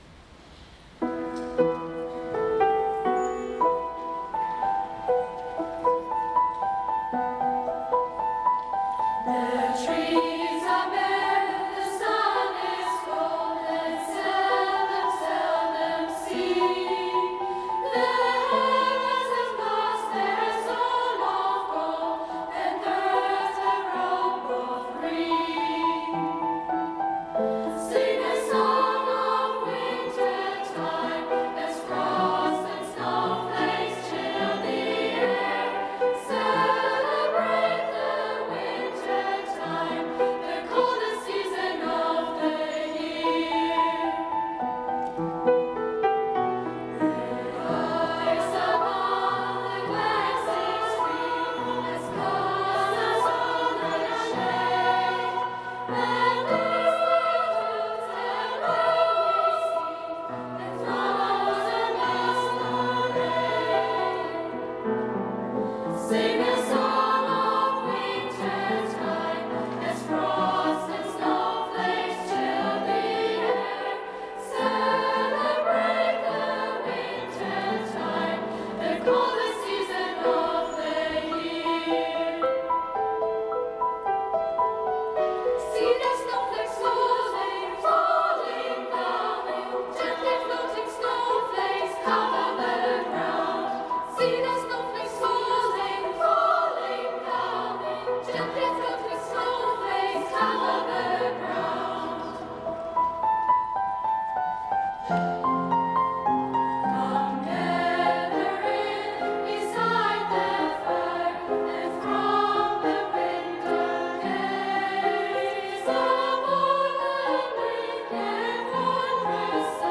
Mit wunderbaren Chorklängen, sanften Gitarren- und Bandsounds, atmosphärischer orchestraler Sinfonik und Orgelmusik von der Empore stimmten uns die großen musikalischen Ensembles am 11. Dezember in St. Gabriel auf Weihnachten 2024 ein.
"Wintertime" von Audrey Snyder, Kammerchor Jgst. 8-12
Klavier